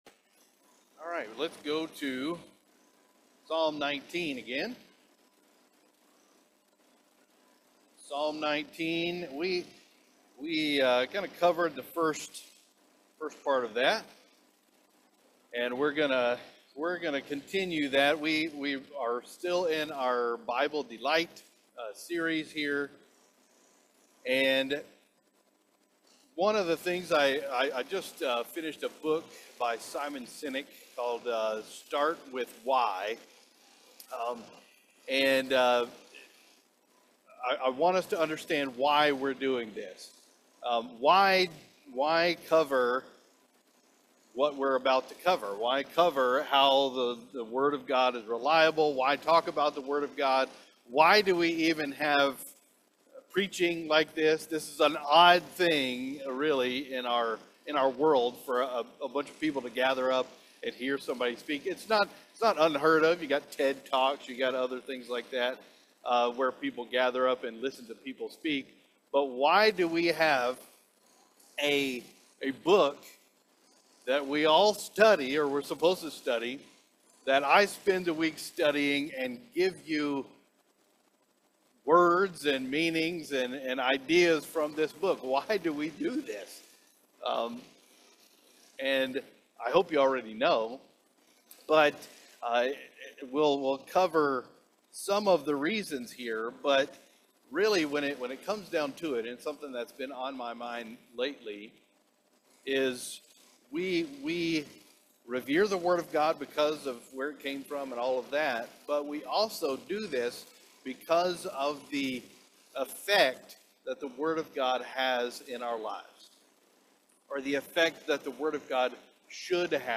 Our latest Sunday morning worship service Messages from God’s word